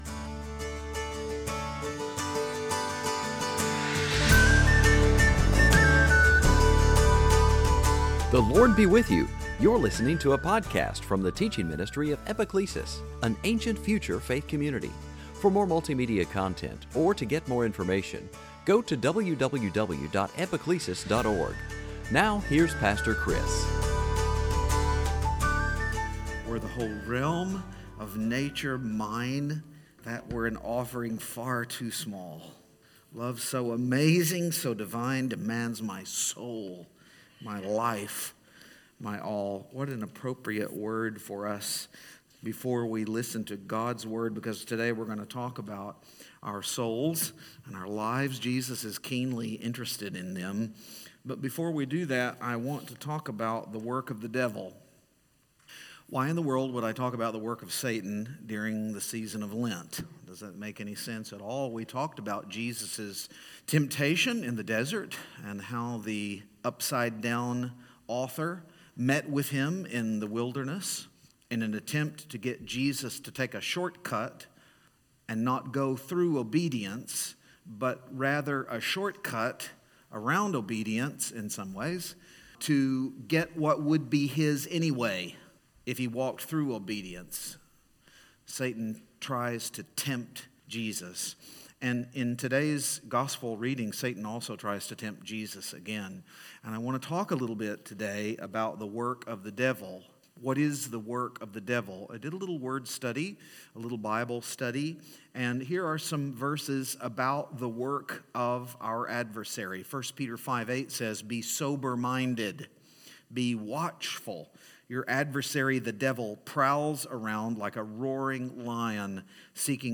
So why would Jesus rebuke Peter and call him Satan? Could we, too, Christ's disciples unwittingly join Satan in his purposes for the world? Join us for a Lenten sermon that calls us to deny ourselves, take up our crosses, join Christ in his sufferings, and die with Him.